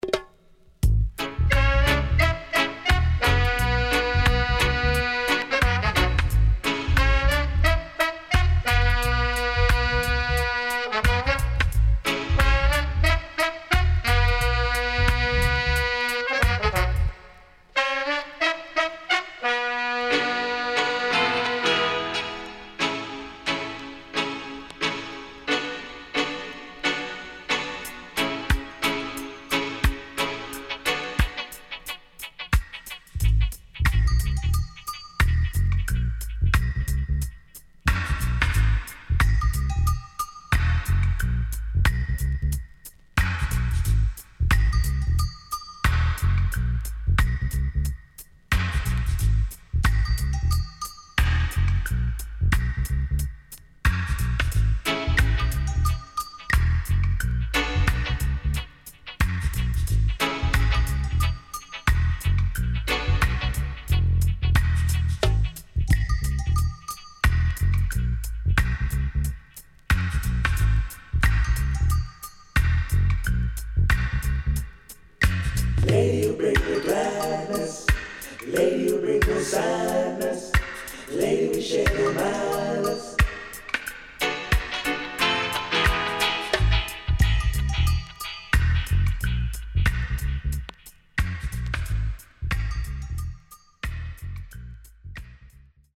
SIDE A:うすいこまかい傷ありますがノイズあまり目立ちません。